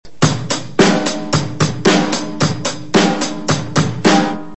I PLAY THE DRUMS!